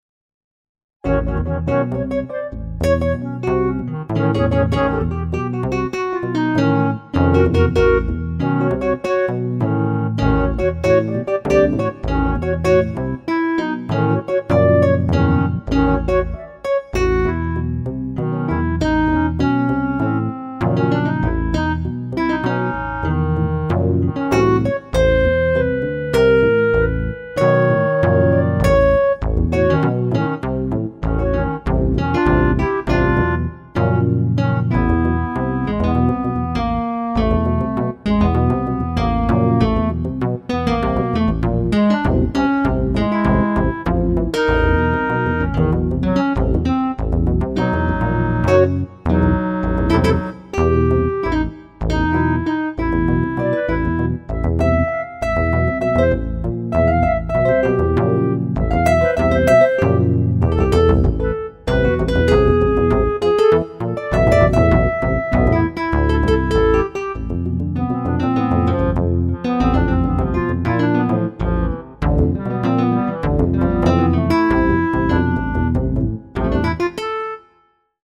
In the same spirit as the last one except that this one was recorded in MIDI so I have the complete transcription.